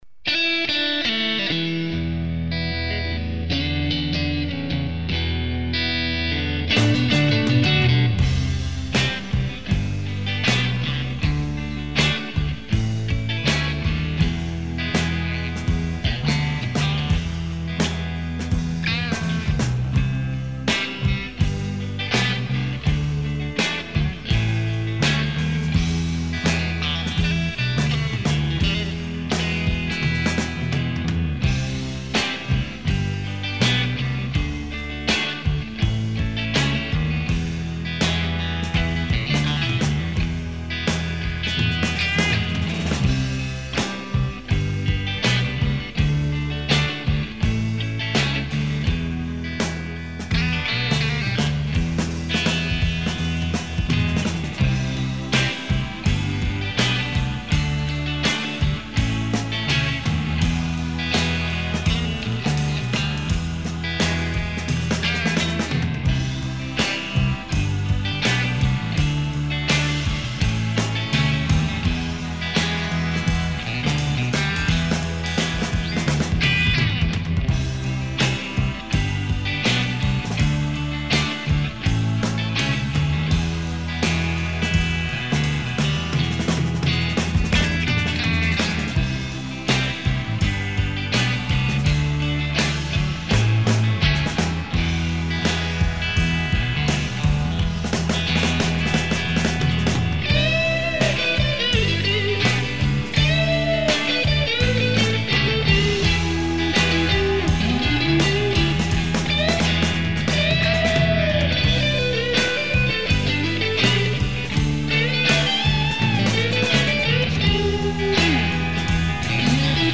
Backing-track(nur Bass, Drums und Backgroundgesang)